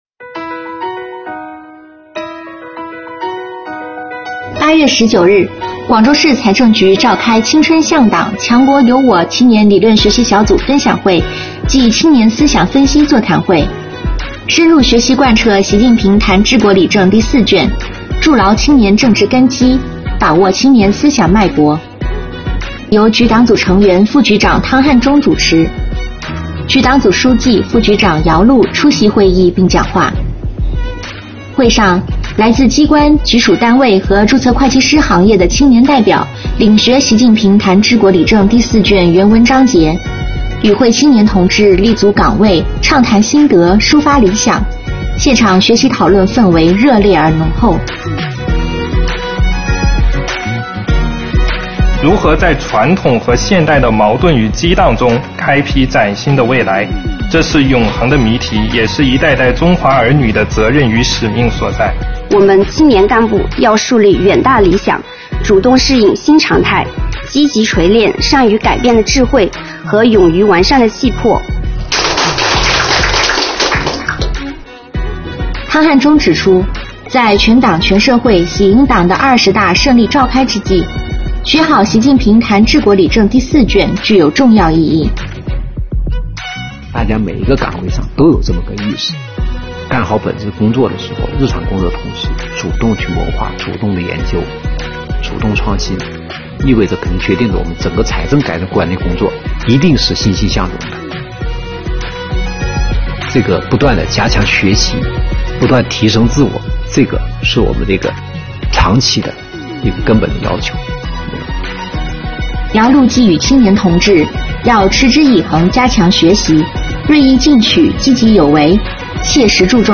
青春向党 强国有我｜广州市财政局召开青年理论学习小组分享会暨青年思想分析座谈会
会议由局党组成员、副局长汤汉忠主持。局党组书记、副局长姚露出席并讲话。
现场学习讨论氛围热烈而浓厚。